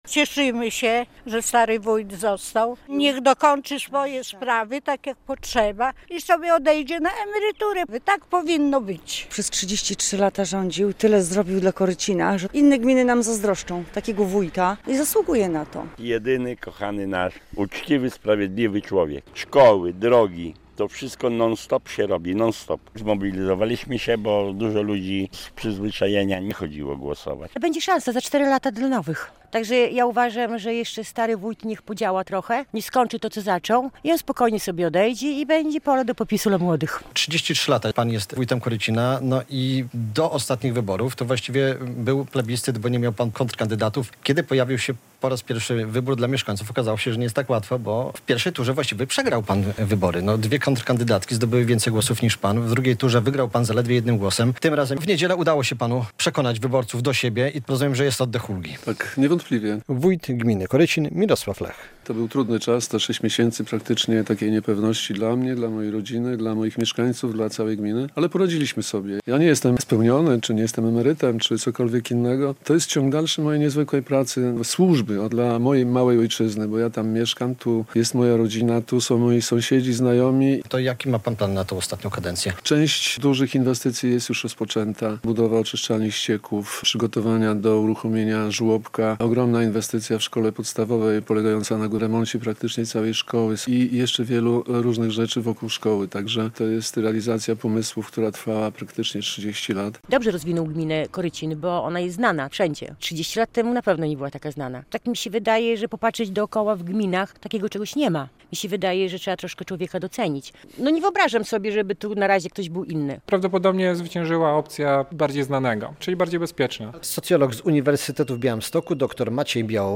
Mieszkańcy gminy Korycin wybrali nowego wójta, którym okazał się dotychczasowy włodarz - relacja